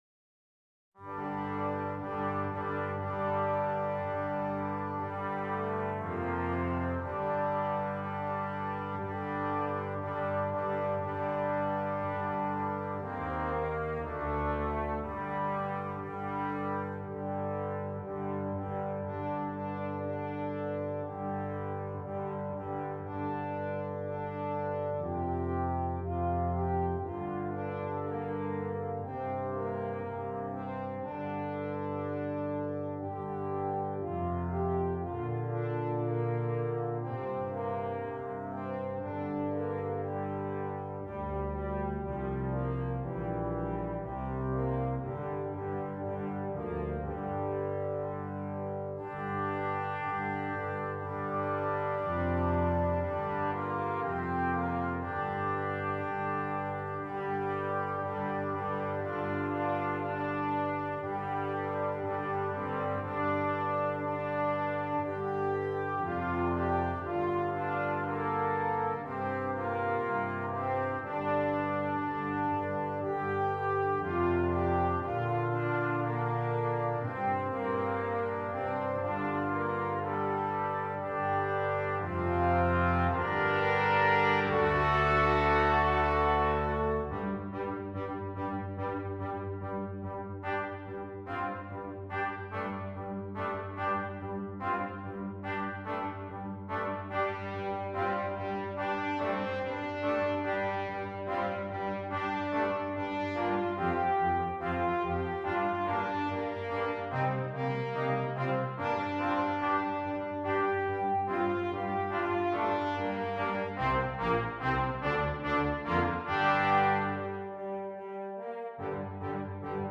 Brass Quintet
Canadian Folk Song